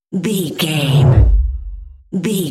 Whoosh deep
Sound Effects
dark
tension
whoosh
sci fi